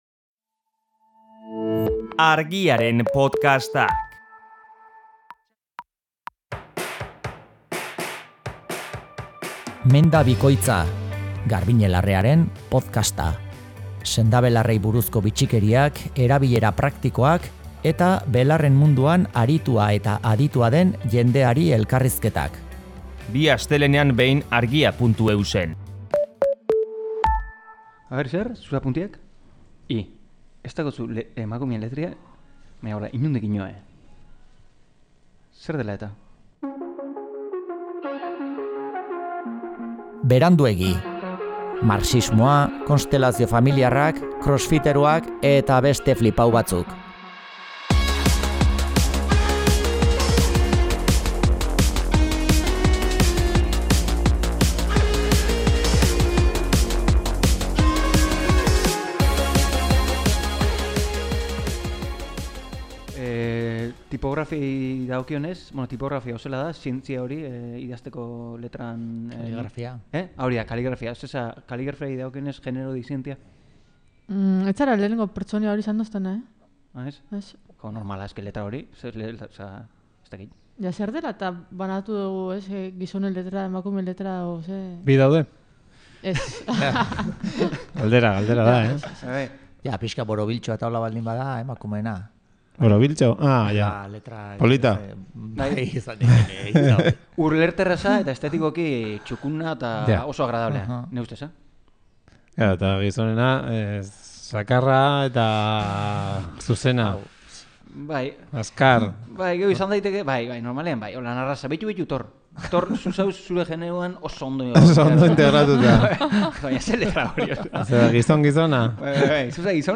Eta behin hau esanda, begiz gaindiko ikusmenak hartu ditu gaurko minutu gehienak. Eta oraingoan entzuteko moduko bolumenean aritu dira.